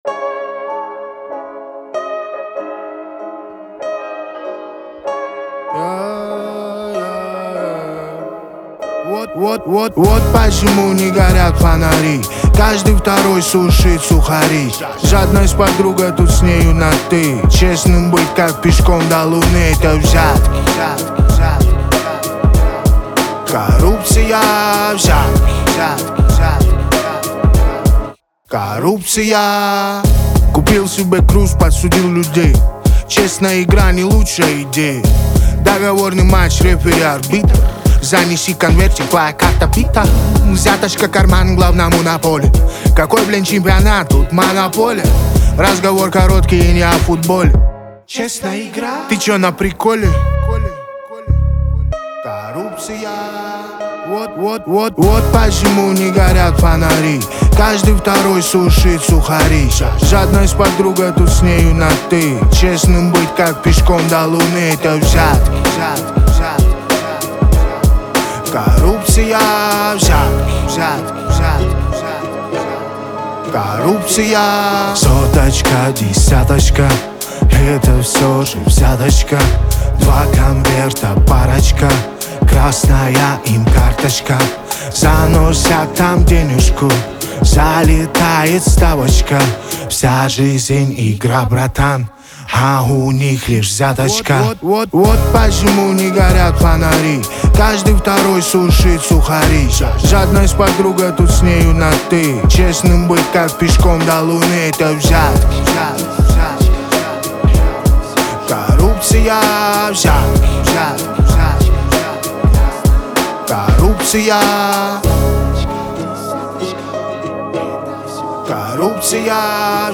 Качество: 320 kbps, stereo
Саундтреки, Поп музыка